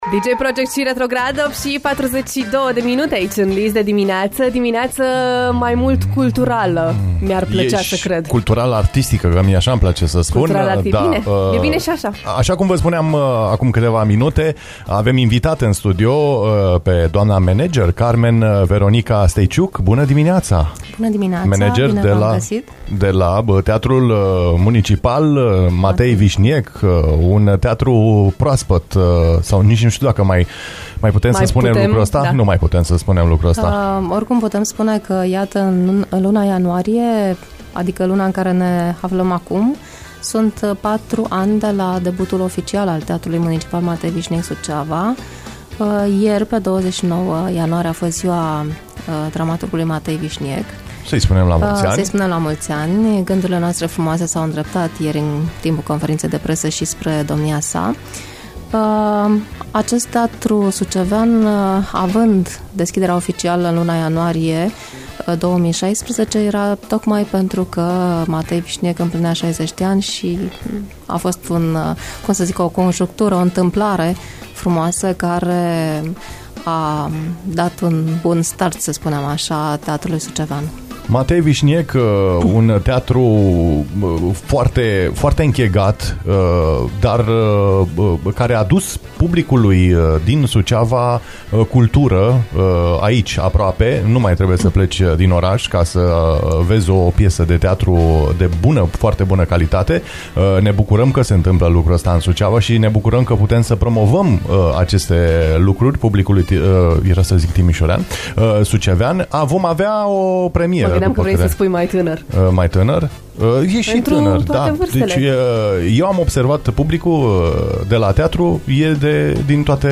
interviu-curand-aici-va-fi-pustiu.mp3